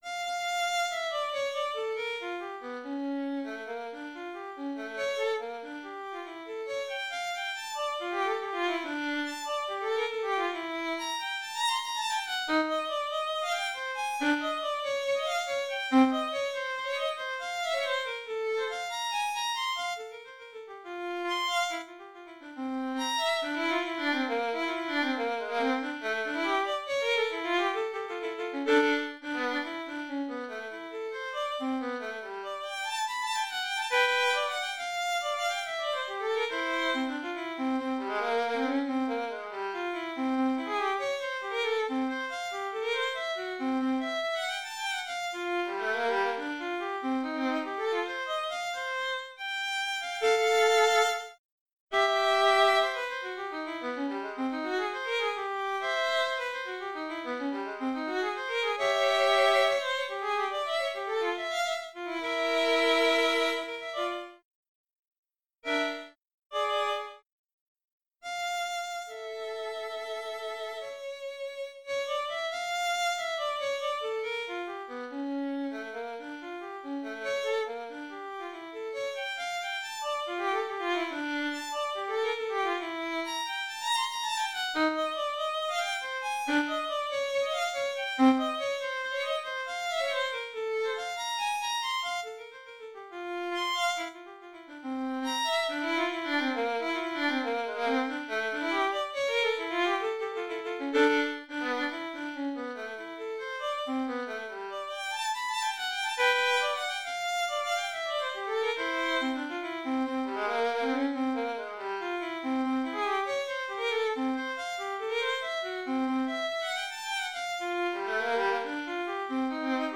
I started with Bach's partita in D minor and warped it.